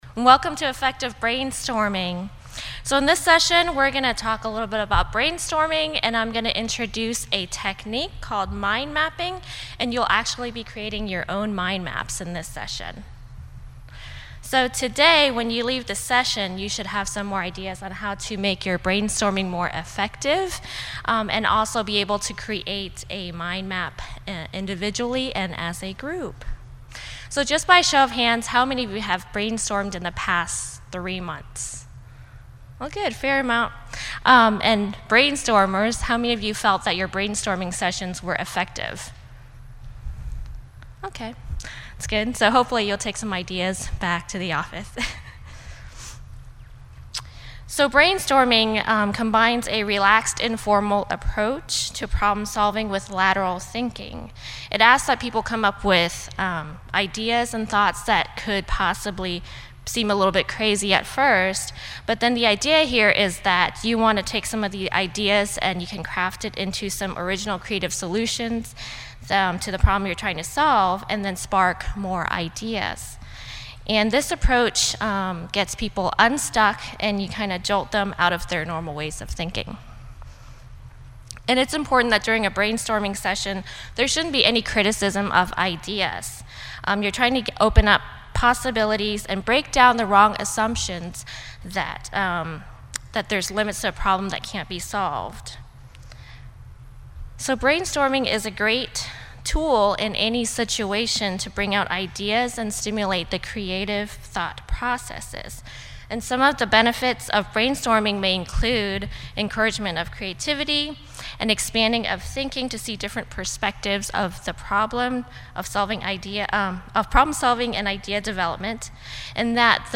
How can we get more out of a brainstorming session? In this interactive session we will explore effective brainstorming techniques and activities.